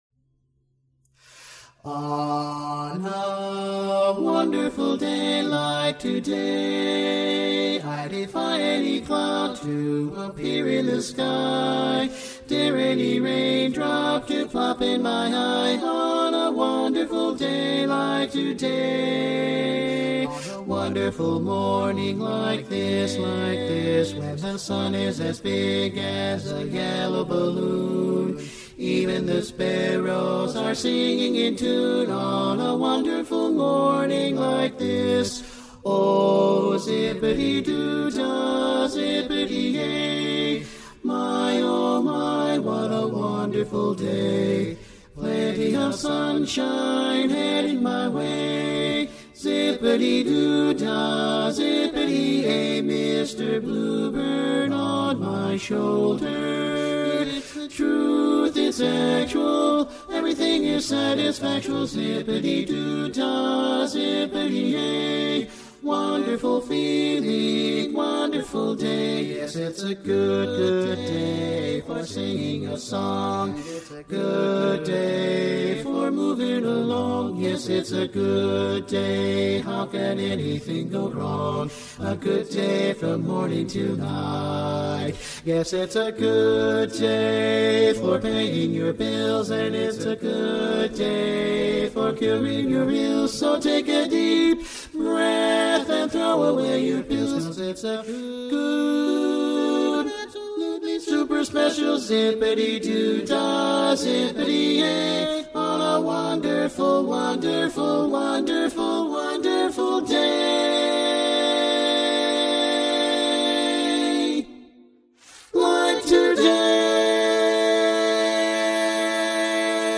G Major
Bari